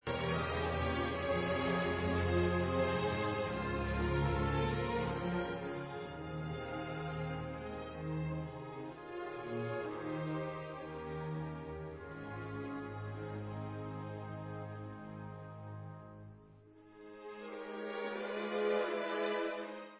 g moll (Presto) /Furiant